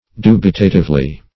Meaning of dubitatively. dubitatively synonyms, pronunciation, spelling and more from Free Dictionary.
dubitatively.mp3